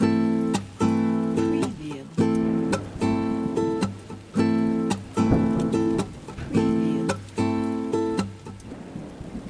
این پکیج شامل تمام آکوردهای مینور و ماژور است که امکان استفاده در ترکیبات موسیقیایی متنوع را به شما می‌دهد.
2. سرعت ریتم ۱۱۰ BPM
3. اجرای Fingerstyle با حس طبیعی
نوع اجرای این لوپ‌ها Fingerstyle است که به معنای نواختن با انگشتان است.
تمامی سمپل‌ها در استودیو اختصاصی هنر صدا با استفاده از گیتار نایلون یا همان گیتار کلاسیک رکورد شده‌اند. ضبط این پکیج با دو میکروفون به صورت همزمان (استریو) انجام شده تا بهترین کیفیت صوتی را ارائه دهد.
دموی ریتمهای پکیج لوپ ۲/۴ گیتار نایلون شماره دو